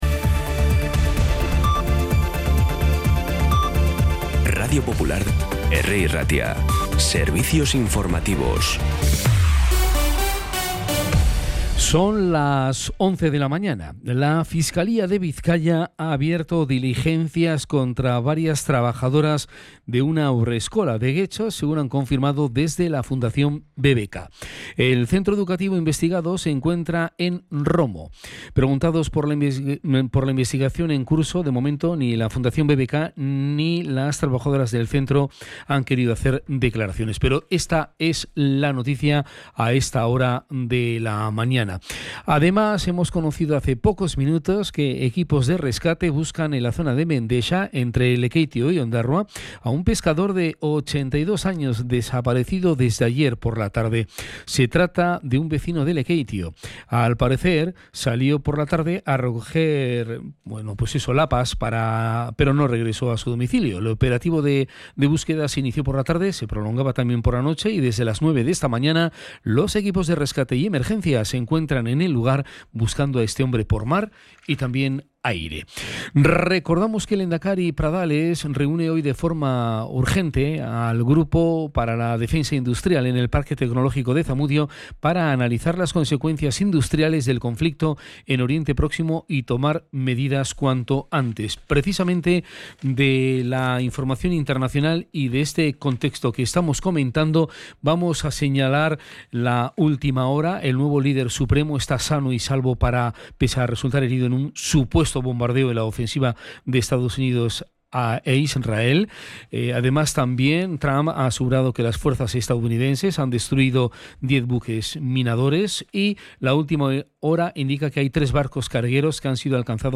La última hora más cercana, de proximidad, con los boletines informativos de Radio Popular.
Los titulares actualizados con las voces del día. Bilbao, Bizkaia, comarcas, política, sociedad, cultura, sucesos, información de servicio público.